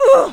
hurt1.ogg